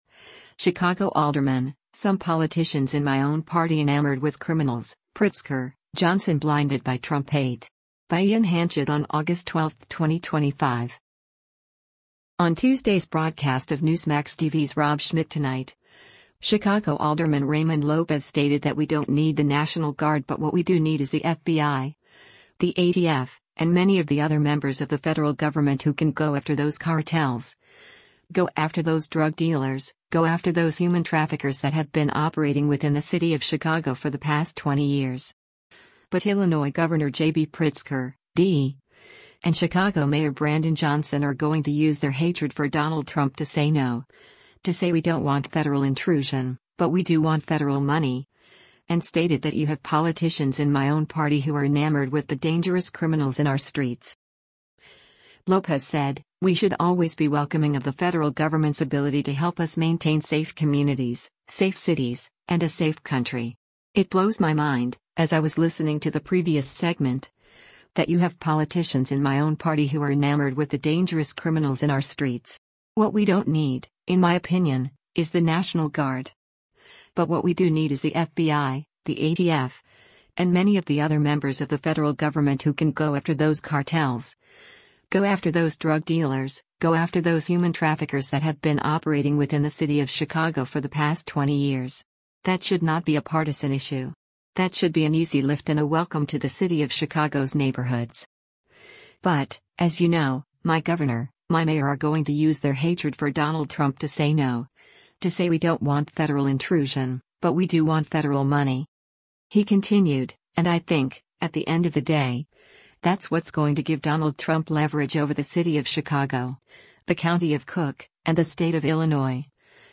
On Tuesday’s broadcast of Newsmax TV’s “Rob Schmitt Tonight,” Chicago Alderman Raymond Lopez stated that “we don’t need” the National Guard but what “we do need is the FBI, the ATF, and many of the other members of the federal government who can go after those cartels, go after those drug dealers, go after those human traffickers that have been operating within the city of Chicago for the past 20 years.”